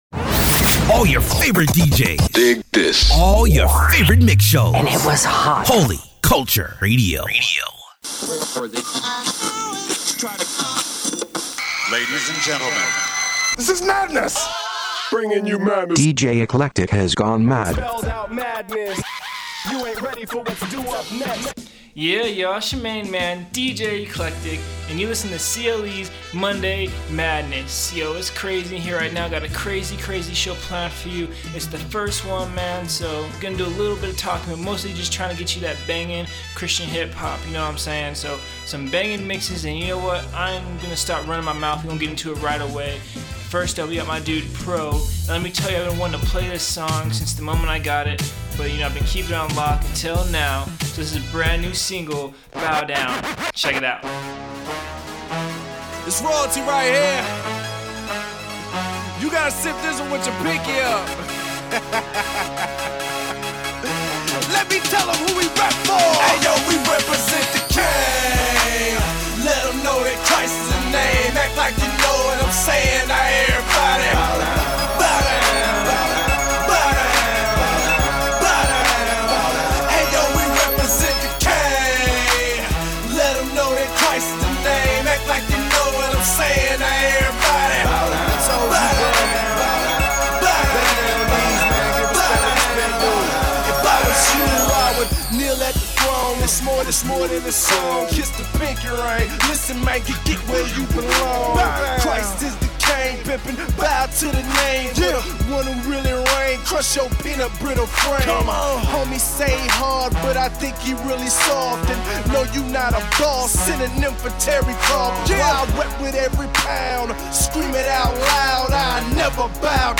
CHH/HHH